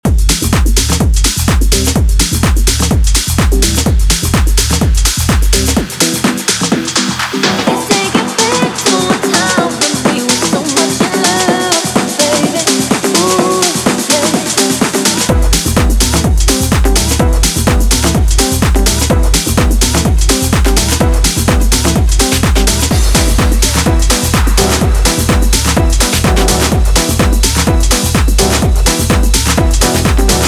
Mixing & Mastering